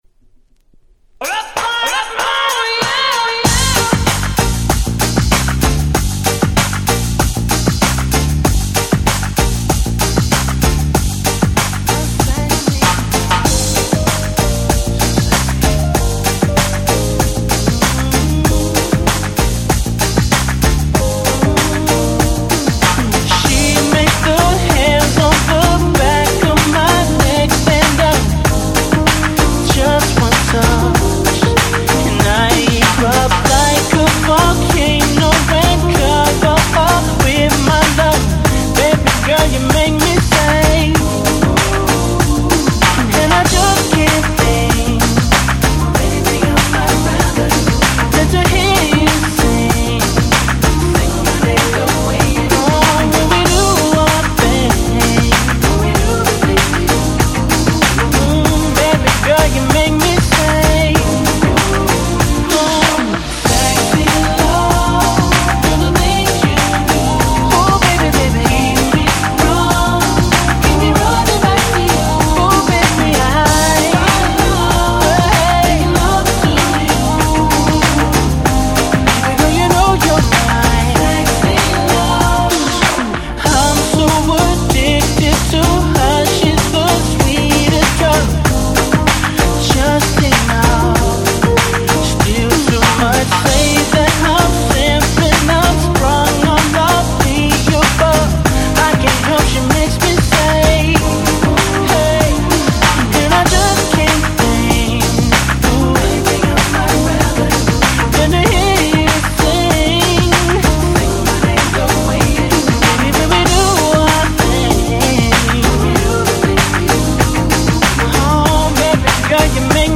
これがまた謎のAce Beatグランドみたいになっていてなんともくすぐられます()